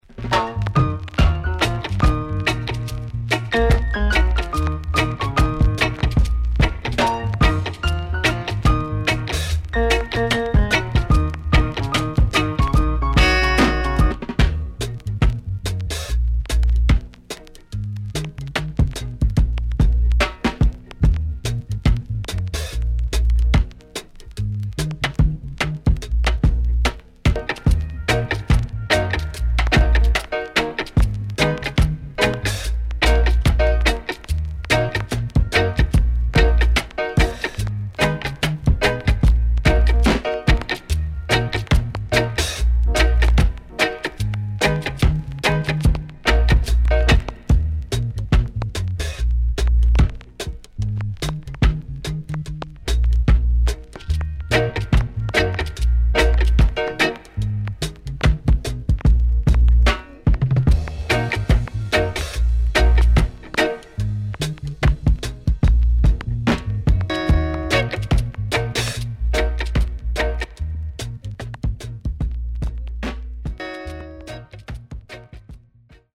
70’s DEEJAY
SIDE A:少しプチパチノイズ入ります。